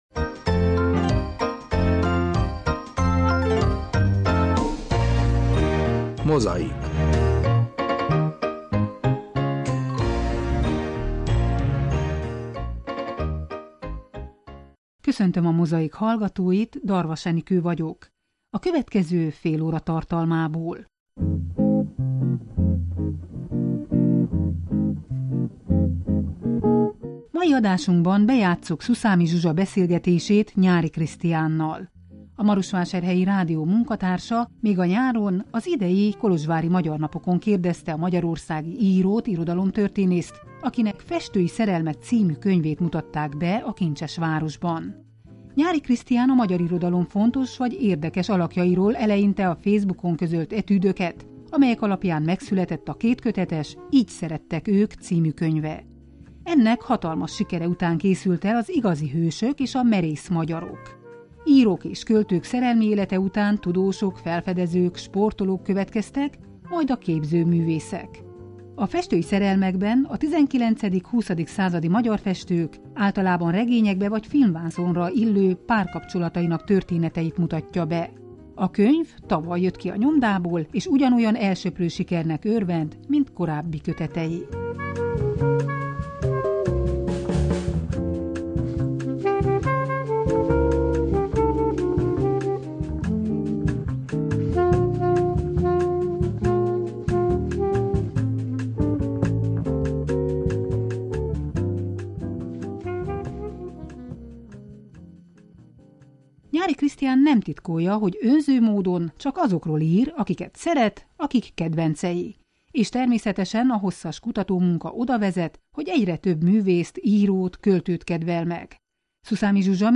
Beszélgetés Nyáry Krisztiánnal